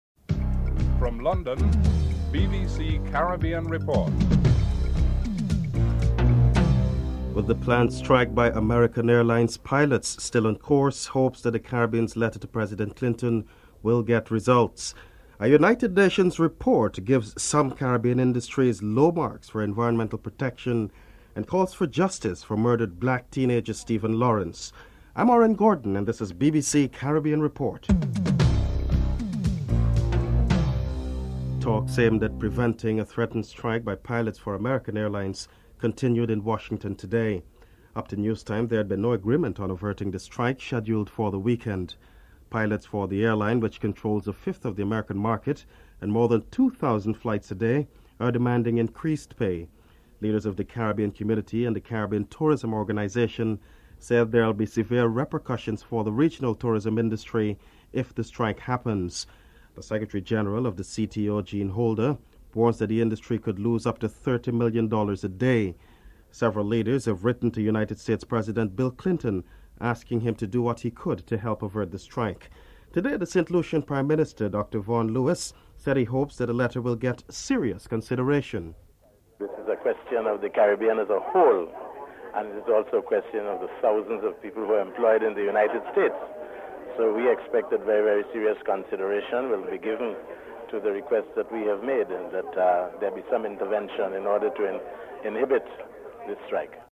1. Headlines (00:00-00:30)
2. The planned strike by American Airlines pilots still on course, hopes that the Caribbean's letter to President Clinton will get results. Vaughn Lewis, Prime Minister of St. Lucia is interviewed (00:31-02:43)